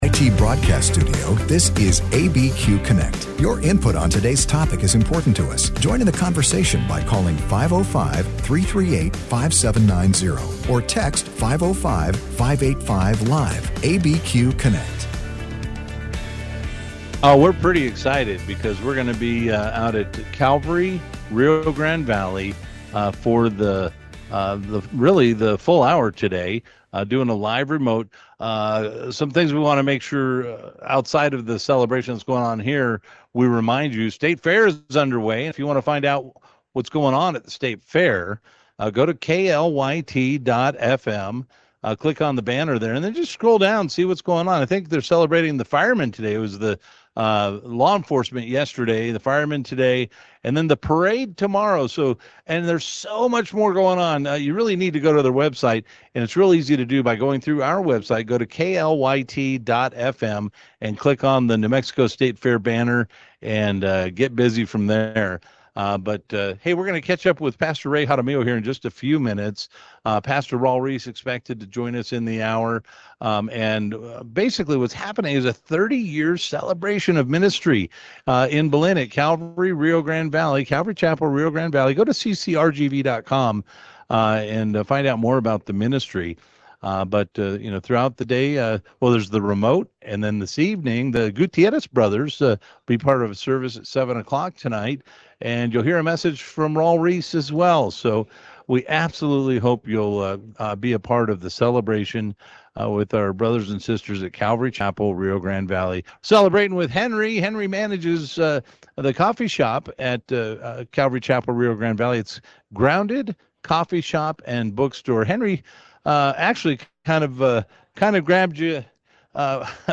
Live Remote from Calvary Chapel Rio Grande Valley